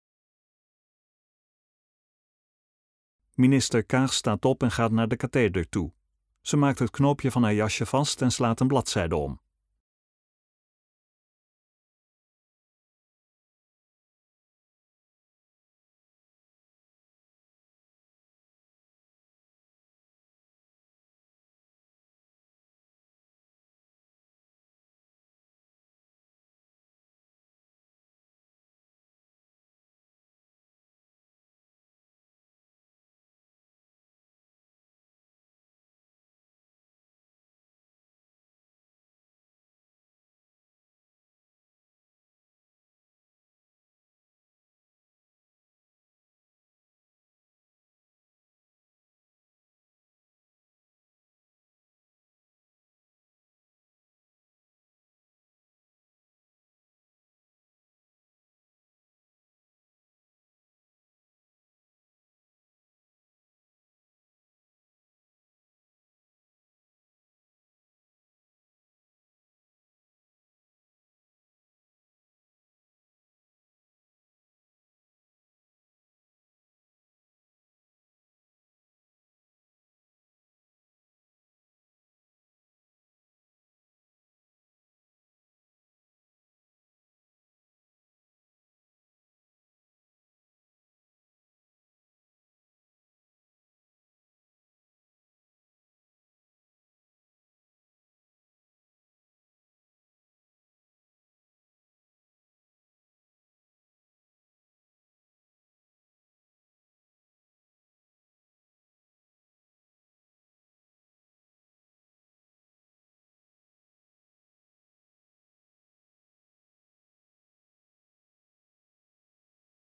Toespraak van minister Kaag (Financiën) bij de aanbieding van de Miljoenennota 2023 aan de Tweede Kamer op 20 september 2022 in Den Haag.